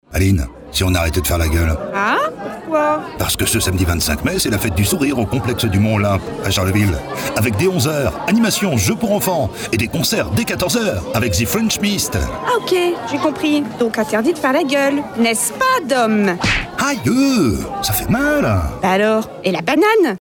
La station de Radio RVM nous a réalisé un spot radio pour annoncer notre évènement tout au long de cette semaine.